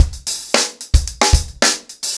TupidCow-110BPM.49.wav